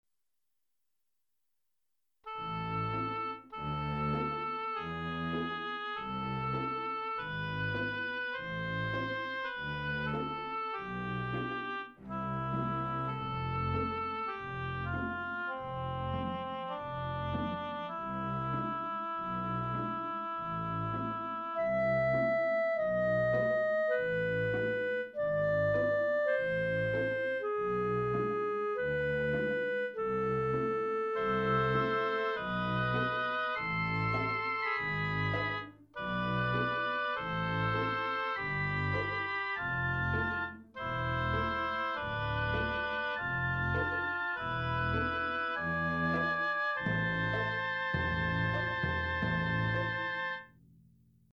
Using keyboard, computer and sound module, writing the score blends with the performance.
just a minute 01’ I constructed, playing around with the Roland JV1010 SR-JV80-02 Orchestral sound module.